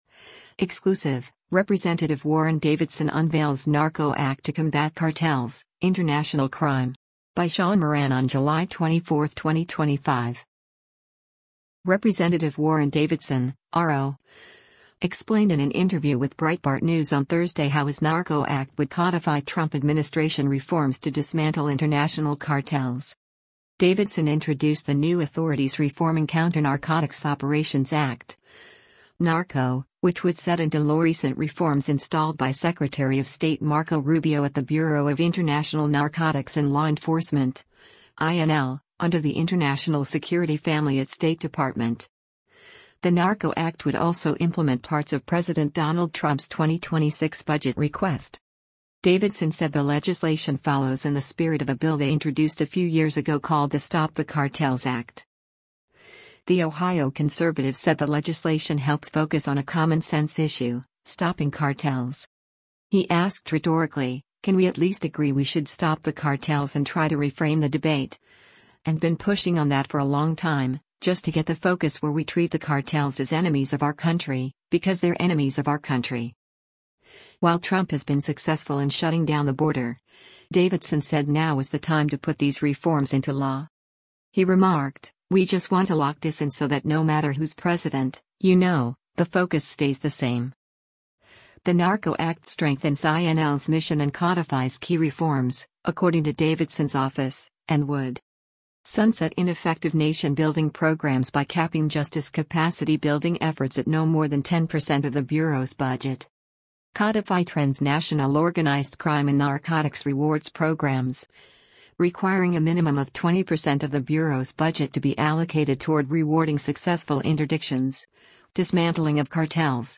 Rep. Warren Davidson (R-OH) explained in an interview with Breitbart News on Thursday how his NARCO Act would codify Trump administration reforms to dismantle international cartels.